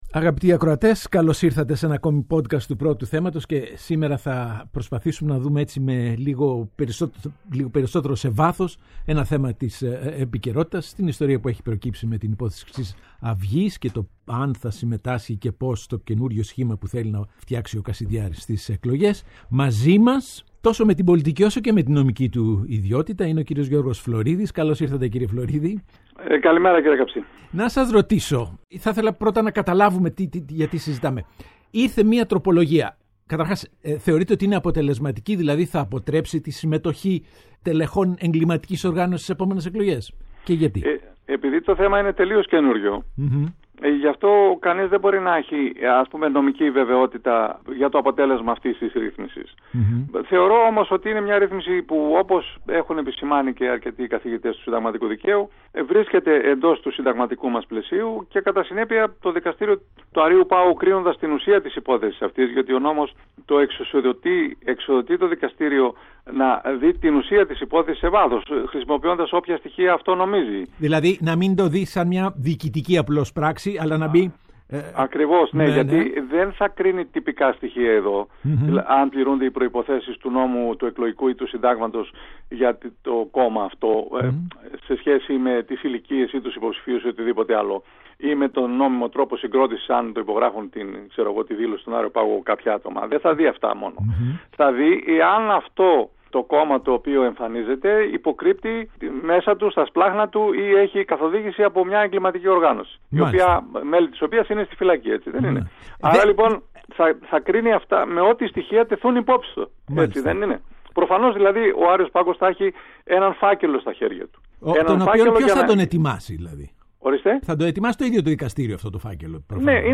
O Παντελής Καψής συζητά με τον Γιώργο Φλωρίδη: Οι ανίερες συμμαχίες της εποχής των Αγανακτισμένων και οι ευθύνες του ΣΥΡΙΖΑ